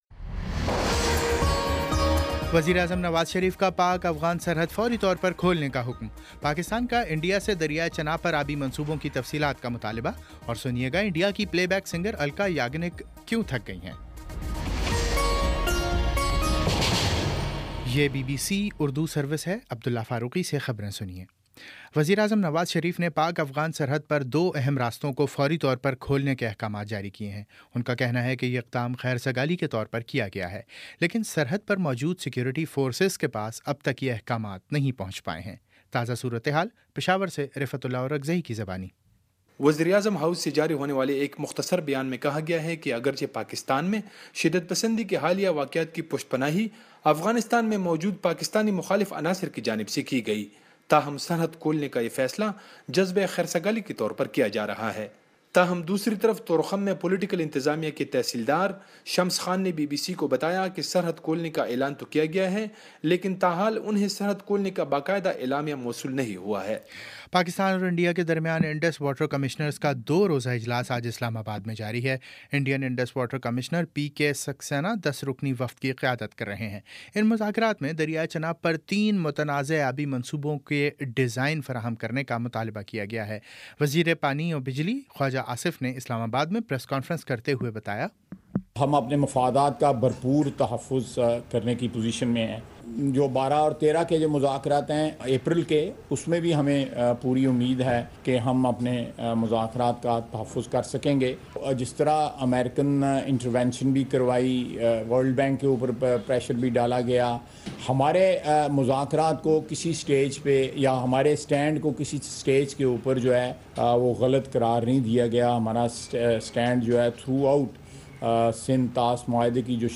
مارچ 20 : شام چھ بجے کا نیوز بُلیٹن